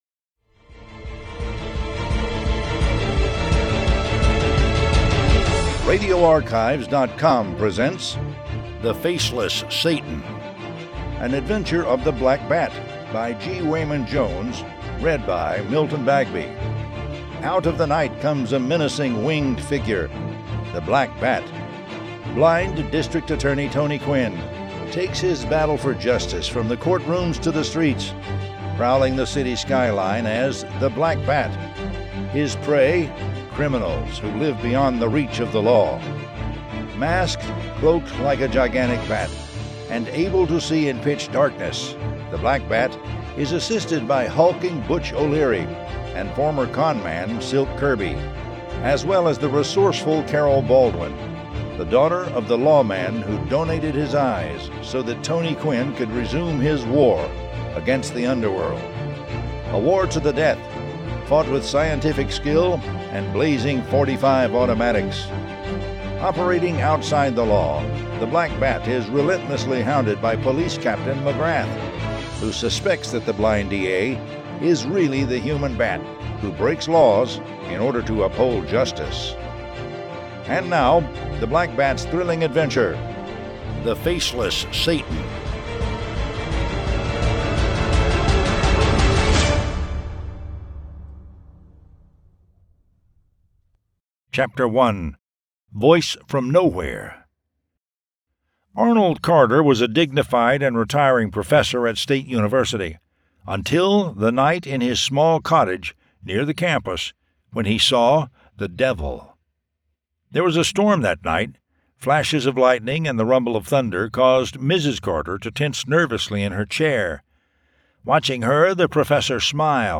The Black Bat Audiobook #19 The Faceless Satan